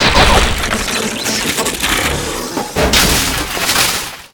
metalprocess.ogg